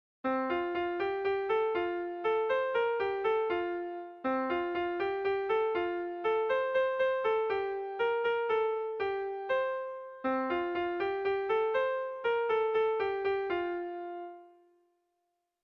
Gabonetakoa
Araba < Euskal Herria
AAB